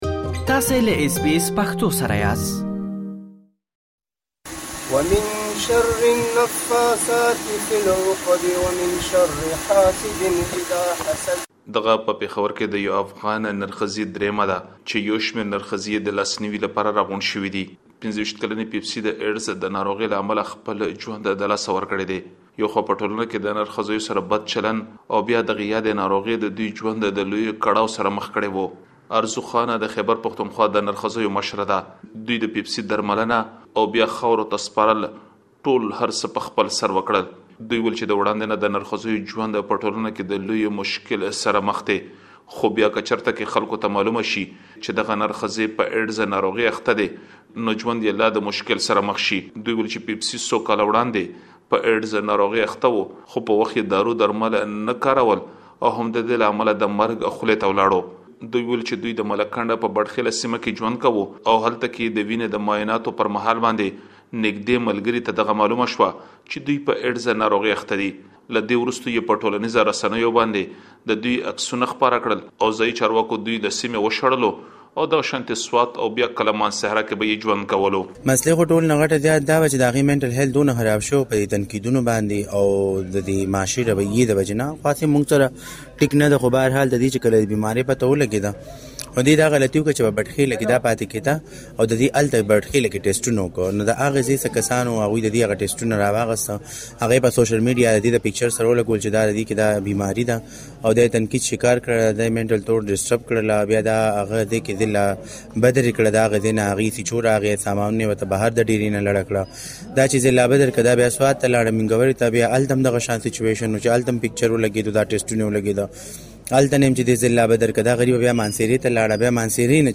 نور مالومات په راپور کې واورئ.